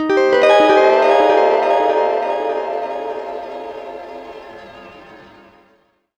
GUITARFX15-L.wav